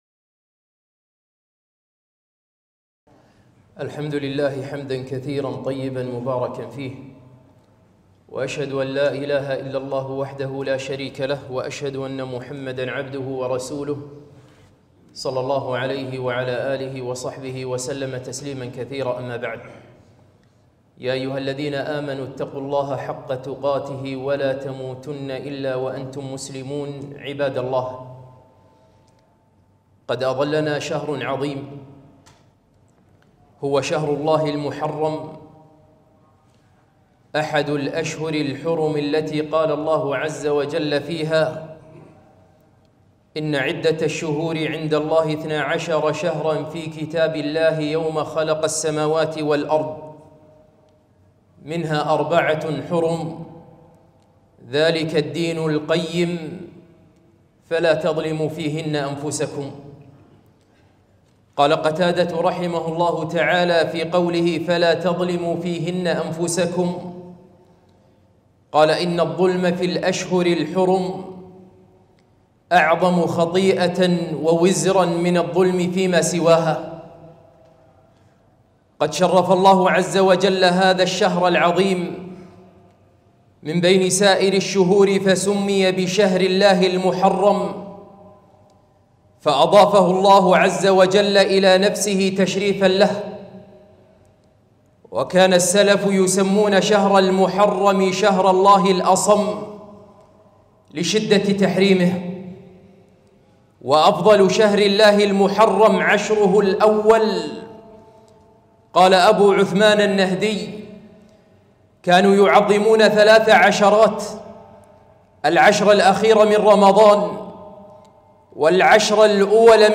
خطبة - شهر الله المحرّم